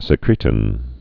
(sĭ-krētn)